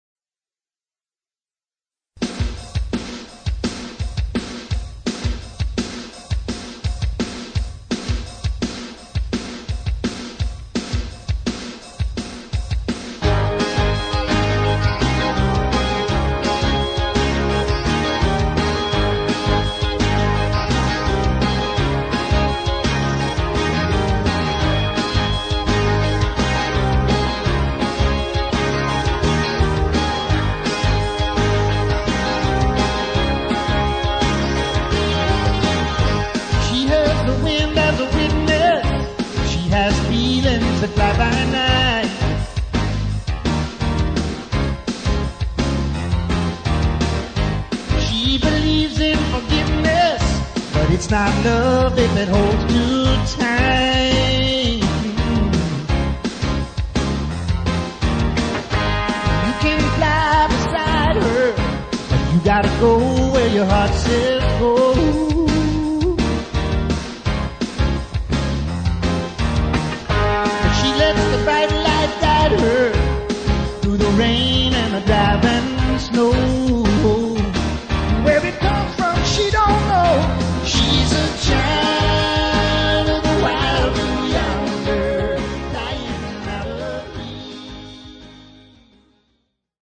please note: demo recording
location: Nashville, date: unknown